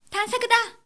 「喜」のタグ一覧
ボイス
女性